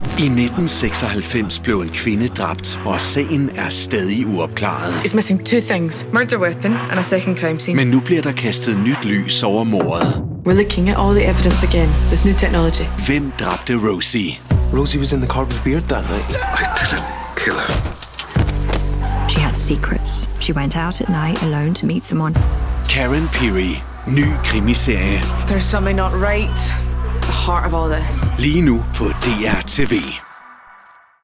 Male
Approachable, Character, Conversational, Corporate, Energetic, Engaging, Friendly, Gravitas, Natural, Reassuring, Sarcastic, Soft, Upbeat, Versatile, Warm
Microphone: Neumann u87